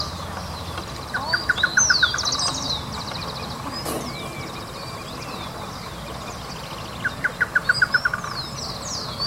Chotoy (Schoeniophylax phryganophilus)
Nombre en inglés: Chotoy Spinetail
Localidad o área protegida: Reserva Natural del Pilar
Condición: Silvestre
Certeza: Vocalización Grabada